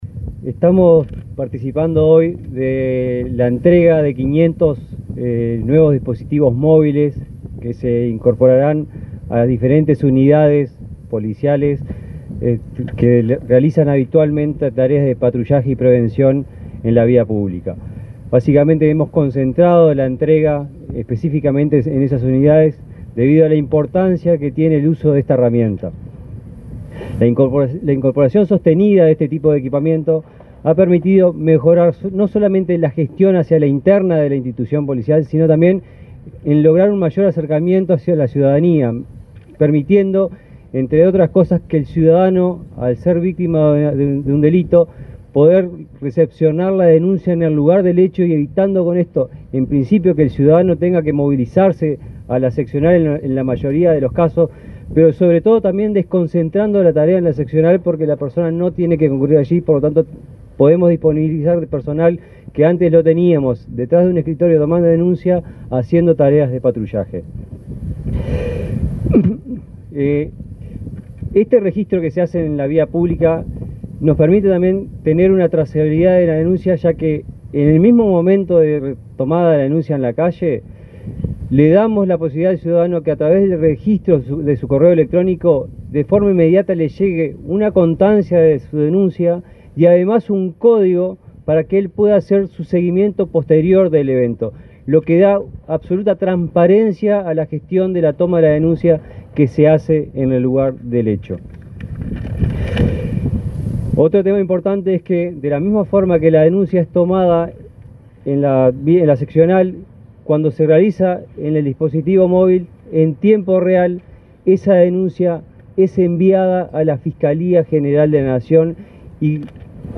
Palabras del director de Sistemas de Información de Ministerio de Interior, Fabricio Fagúndez
Palabras del director de Sistemas de Información de Ministerio de Interior, Fabricio Fagúndez 12/09/2024 Compartir Facebook X Copiar enlace WhatsApp LinkedIn El director de Sistemas de Información de Ministerio de Interior, Fabricio Fagúndez, participó, este jueves 12 en Montevideo, en el acto de entrega de 500 tabletas a efectivos policiales en la Jefatura de Policía de Montevideo.